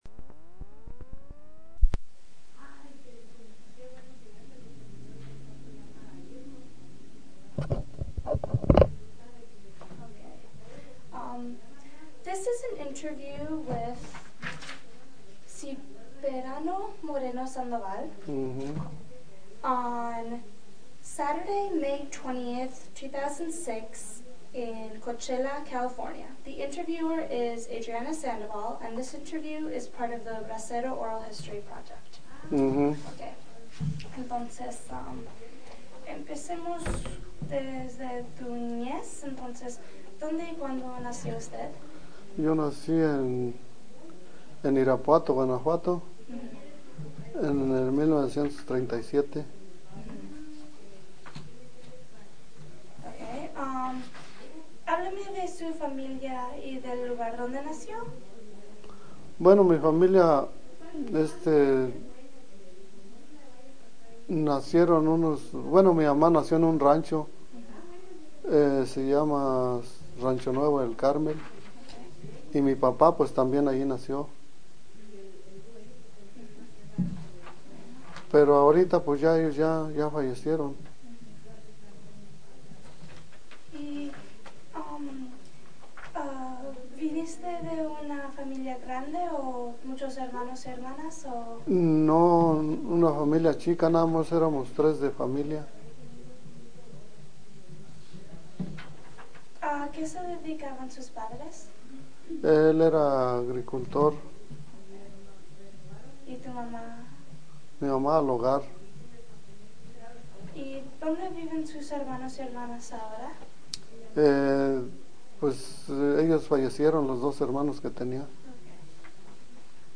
Original Format Mini Disc
Location Coachella, CA